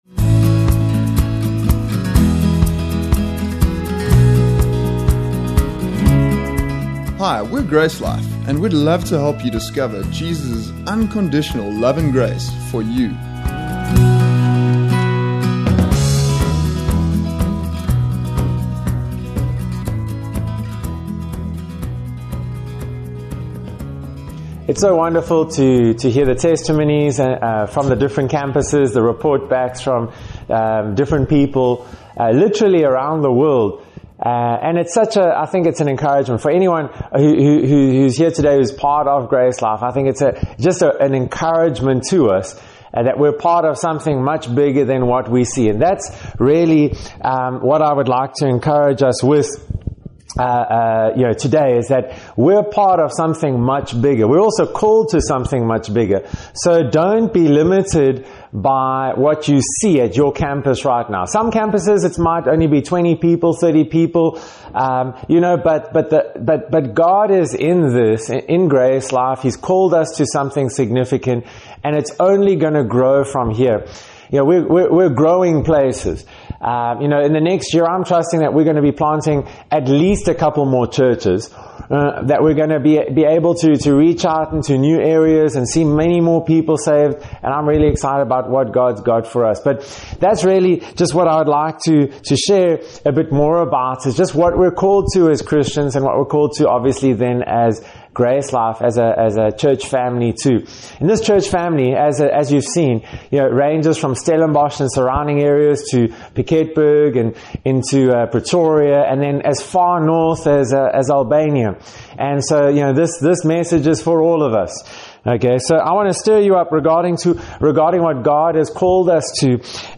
Thanksgiving Service
Thanksgiving-Service.mp3